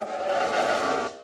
sounds / mob / horse / skeleton / idle1.mp3